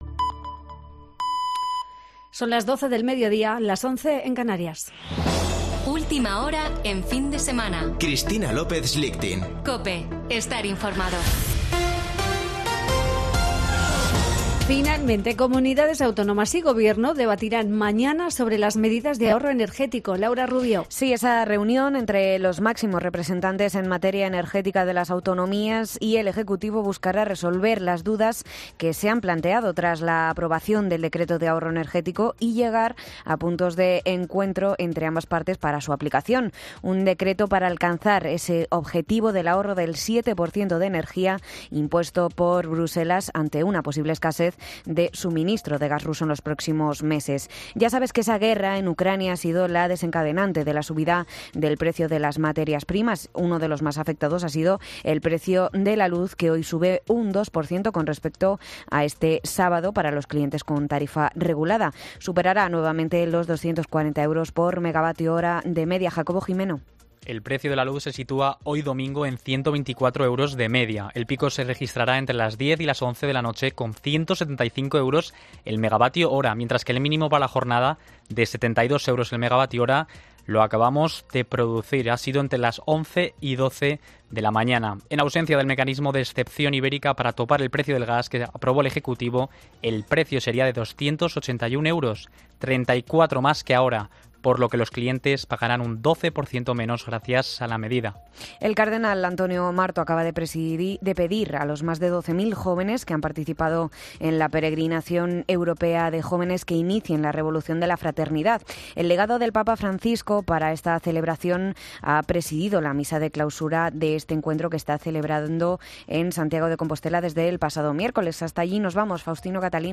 Boletín de noticias de COPE del 7 de agosto de 2022 a las 12.00 horas